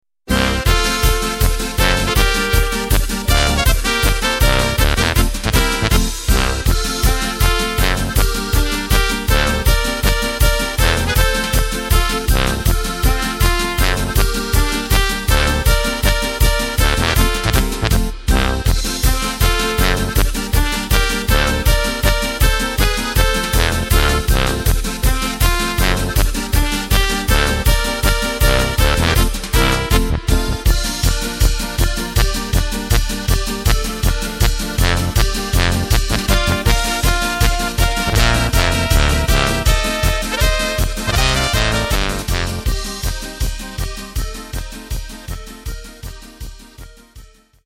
Takt:          2/4
Tempo:         160.00
Tonart:            F
Flotte Polka aus dem Jahr 1991!
Playback mp3 Mit Drums